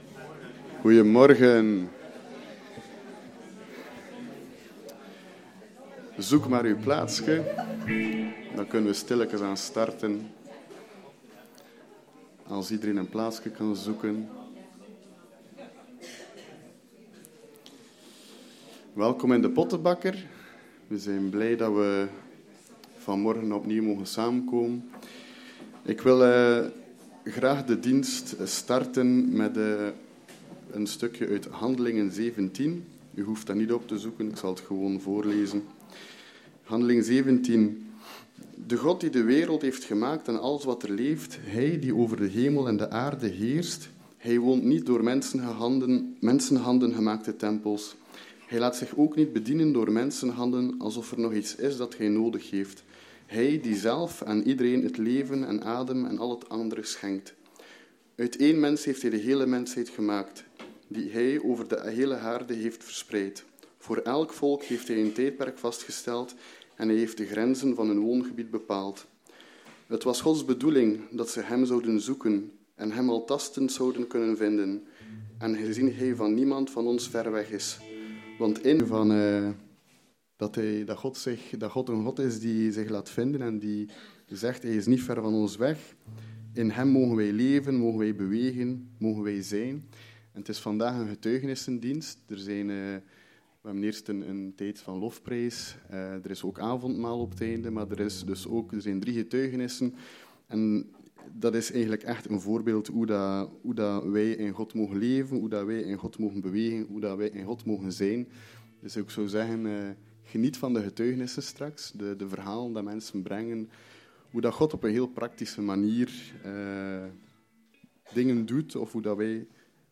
GETUIGENISSENDIENST